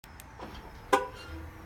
Play Mercedes Lock Sound - SoundBoardGuy
Play, download and share Mercedes Lock Sound original sound button!!!!
mercedeslocksound1.mp3